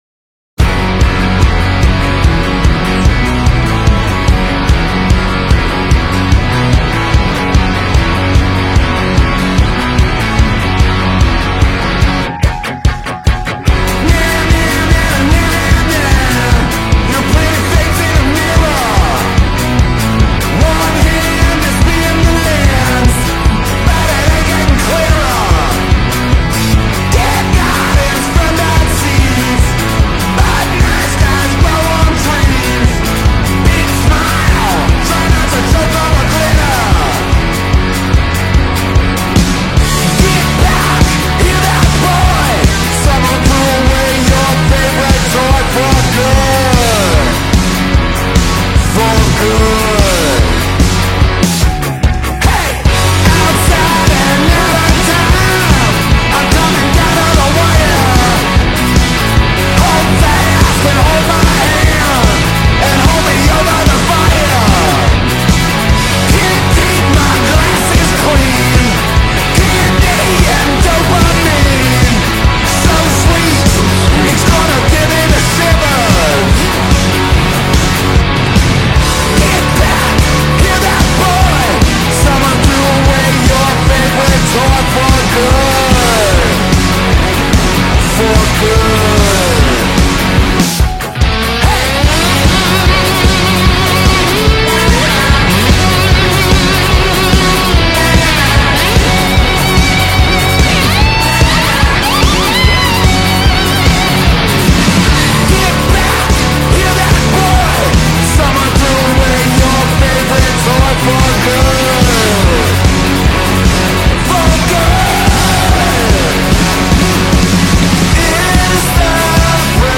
What makes it work is how effortless it feels.